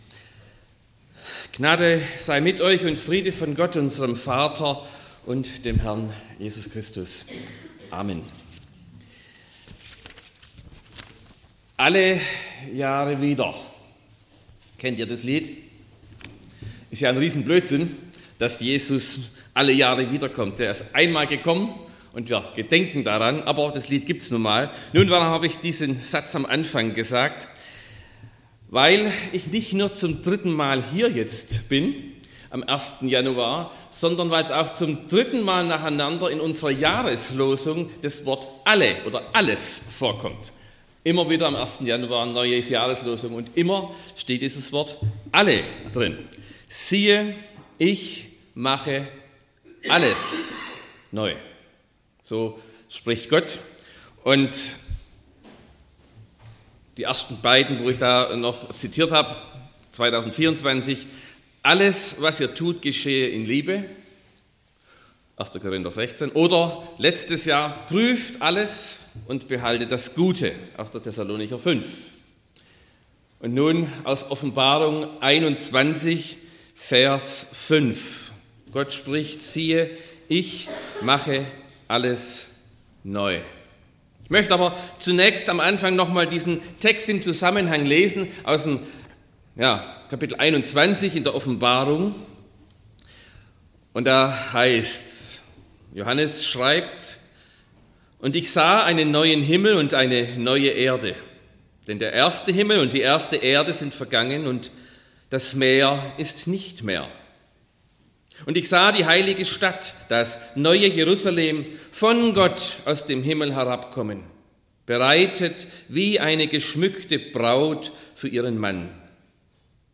01.01.2026 – Gottesdienst
Predigt (Audio): 2026-01-01_Siehe__ich_mache_alles_neu_.mp3 (21,8 MB)